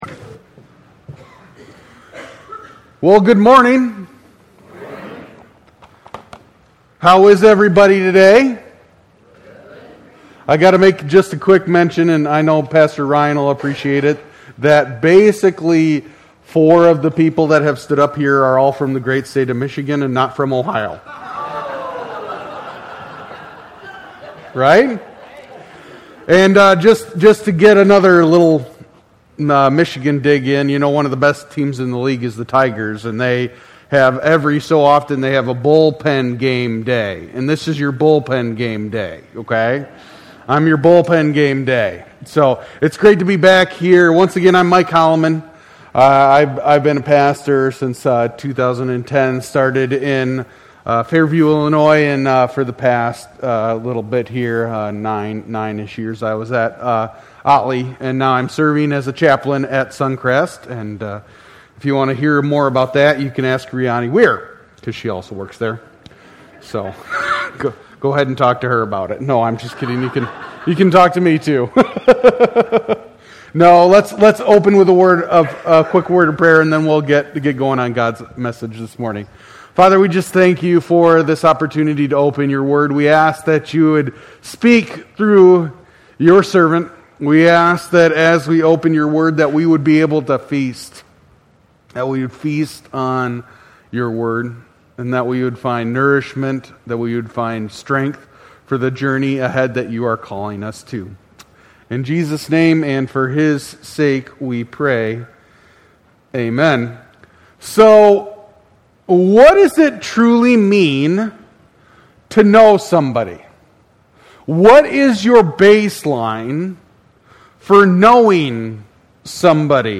at Cornerstone Church